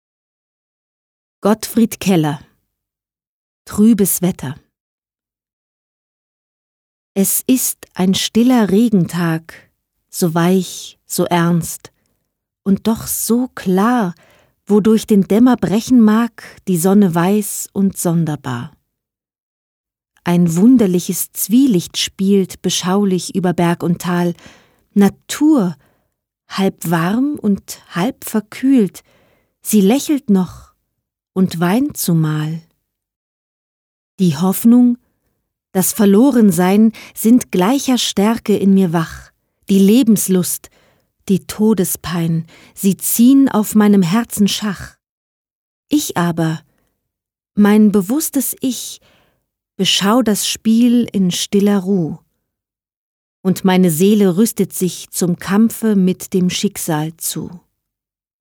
Lyrik, Schullektüre
Trübes Wetter – Gedicht von Gottfried Keller (1819-1890)